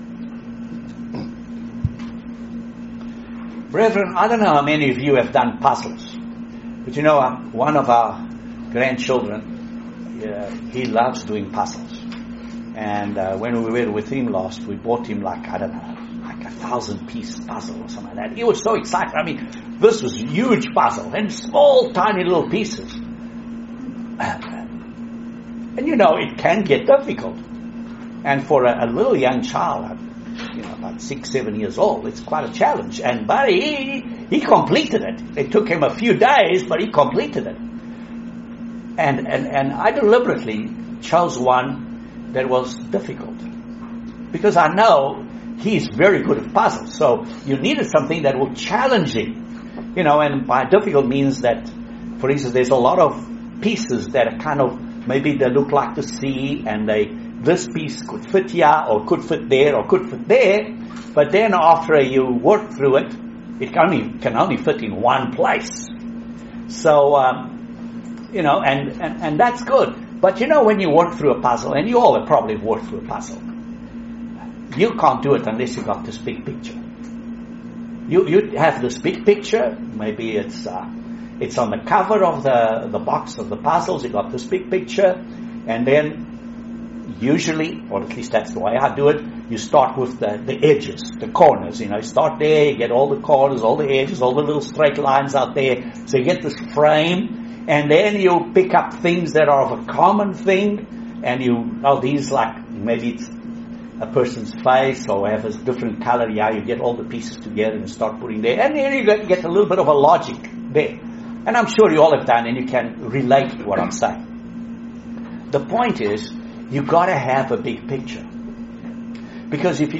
Join us for this very interesting Video sermon on the Events after Christ's 2nd coming. This sermon answers lots of questions about God's fall Holy days.